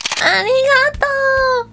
Worms speechbanks
CollectArm.wav